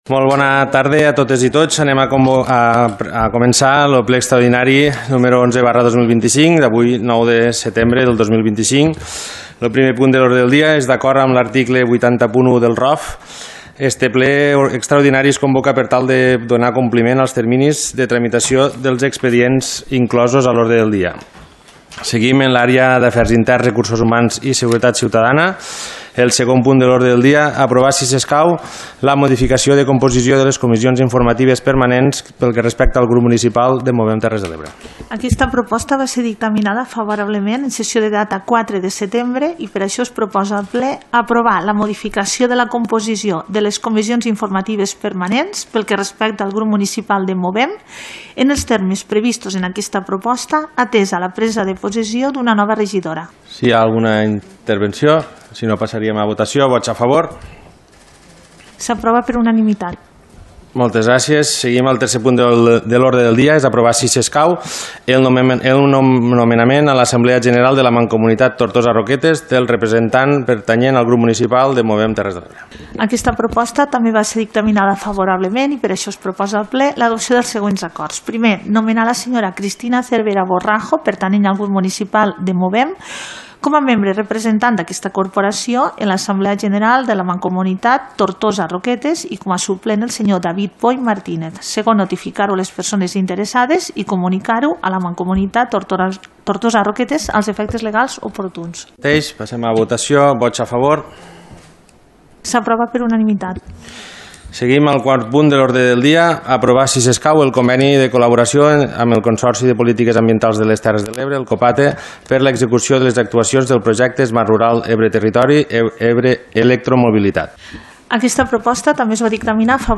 Aquest dimarts 9 de setembre s’ha celebrat a la sala de sessions de l’Ajuntament de Roquetes el Ple extraordinari número 11/2025, corresponent al mes de setembre.